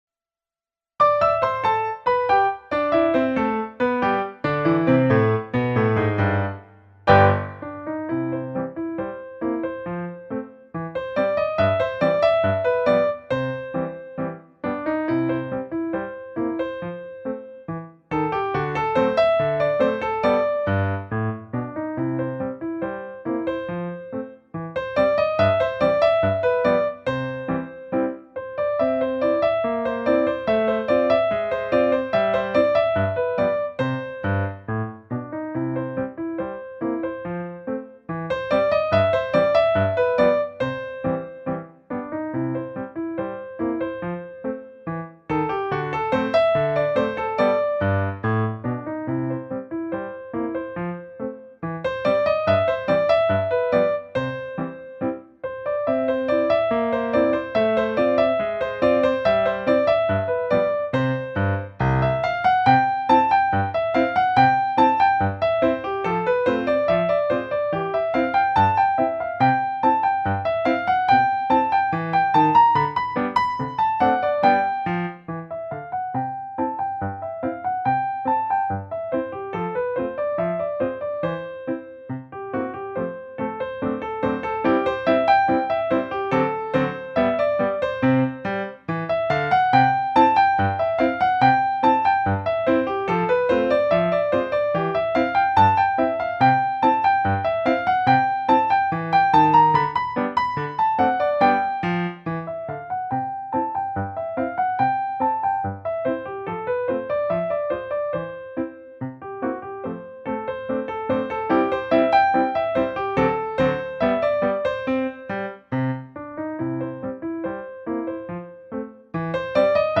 Posłuchaj nieco łatwiejszej wersji tego znanego standardu jazowego:
THE-ENTERTAINER-Easy-version-SCOTT-JOPLIN-Ragtime.mp3